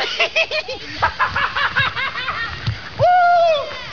Repo’s laugh!
repolaff.wav